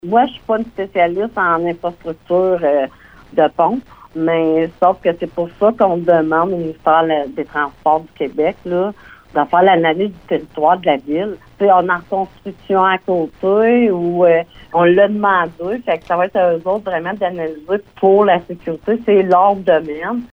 Voici les explications de la mairesse de Maniwaki, Francine Fortin